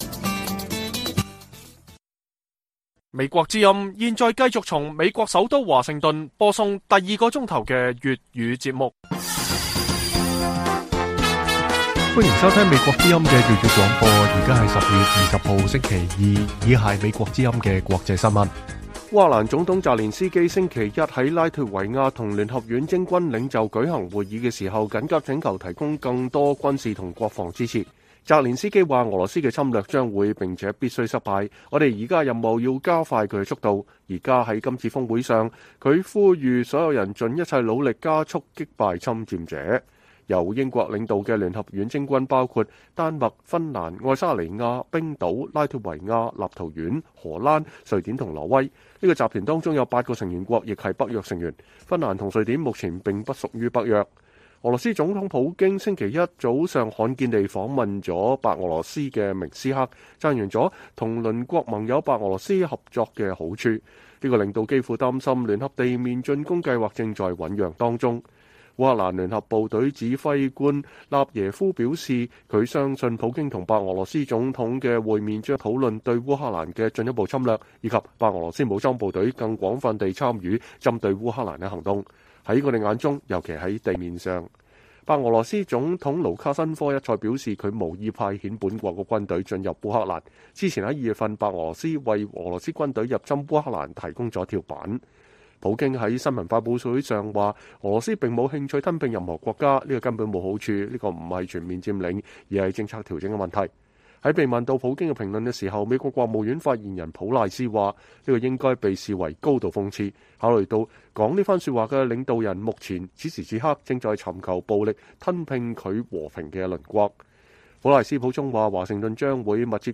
粵語新聞 晚上10-11點: 俄羅斯發起新一輪襲擊 澤連斯基呼籲更多軍事援助